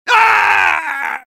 soldier_painsevere05.mp3